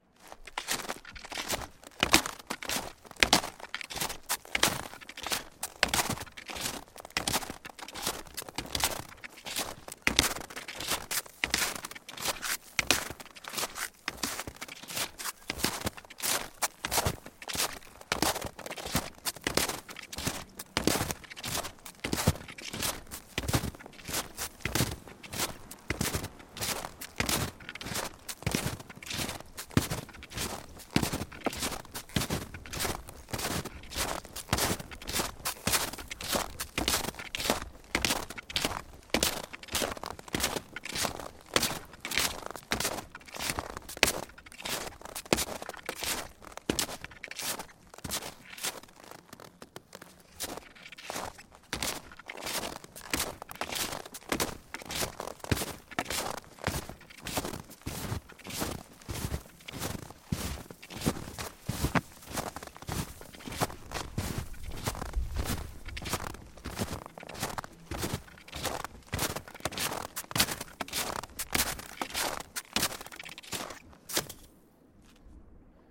冬天 " 脚步声 雪鞋 老木头2 挤满了雪的小路 中速 粘性软
描述：脚步雪鞋老wood2包装雪径中速clacky soft.flac
Tag: 包装 脚步声 雪鞋 越野 雪地 wood2